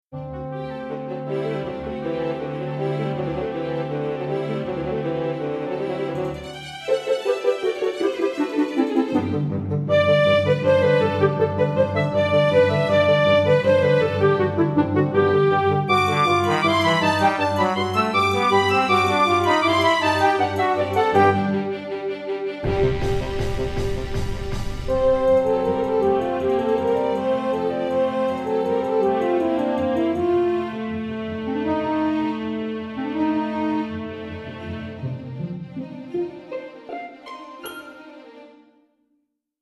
Orchestral Example 1